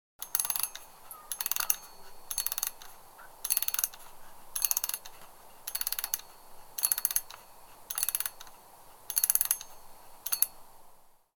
Mechanical Clock Winding Sound Effect
Authentic winding sound of a vintage metal mechanical clock or similar device.
Genres: Sound Effects
Mechanical-clock-winding-sound-effect.mp3